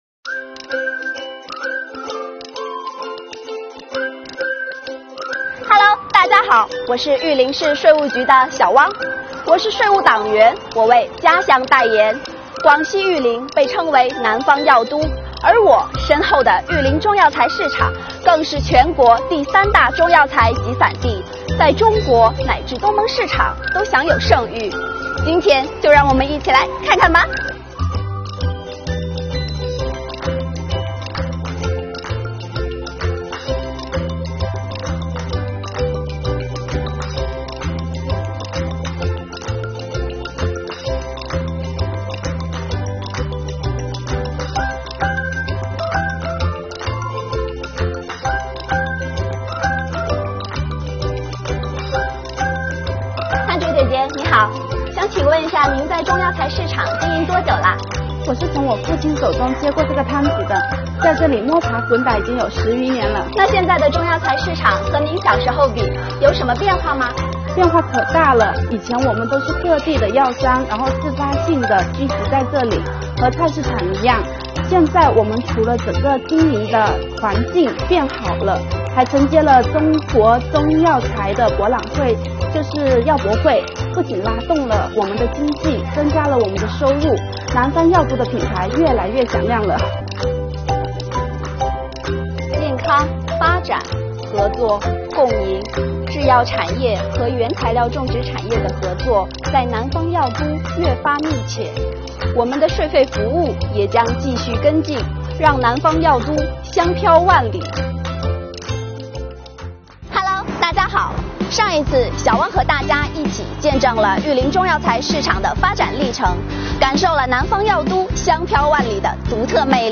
为充分展示党的十八大以来广西税收工作成效，见证税收这十年发展的累累硕果，广西税务局特别策划推出《税火传递·税务党员为家乡代言》栏目，通过税务党员和企业代表共同发声，讲述税企同心促发展的好故事。今天发布的是《税务党员为家乡代言·广西玉林篇》。